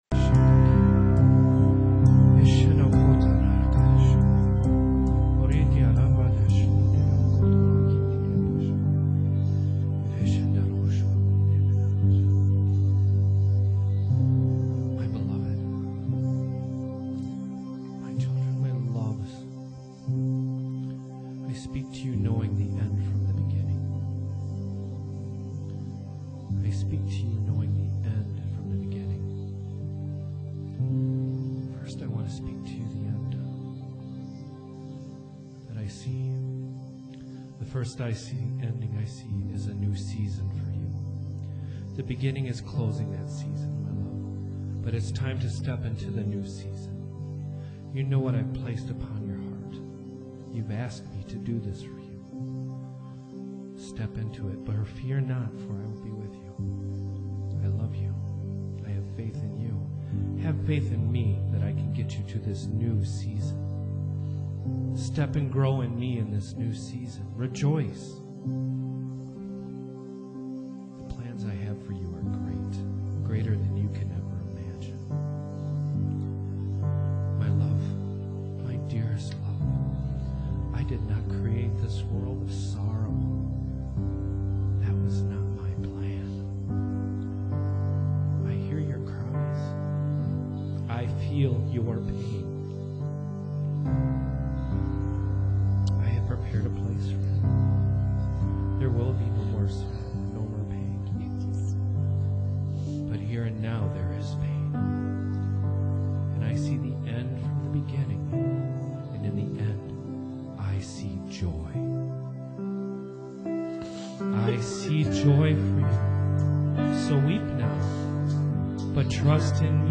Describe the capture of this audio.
Here is a Word that God delivered to us this past month at Janesville Apostolic Ministries.